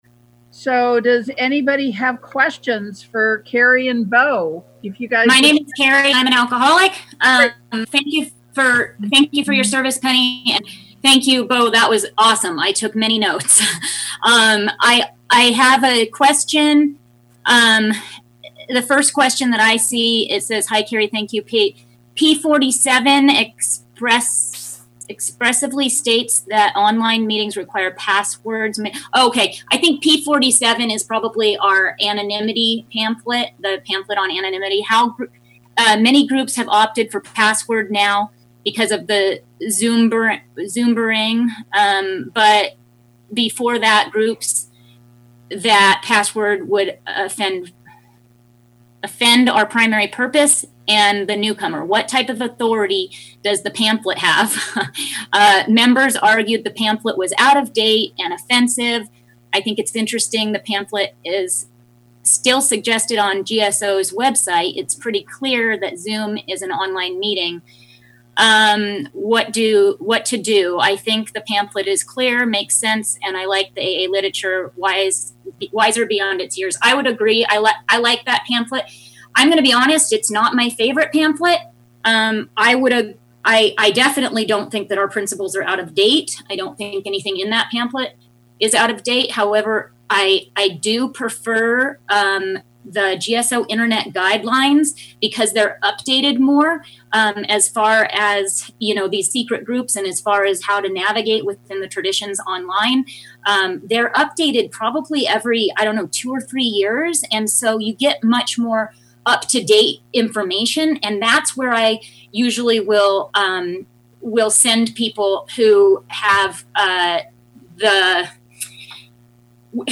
International Corona Service Conference of AA Worldwide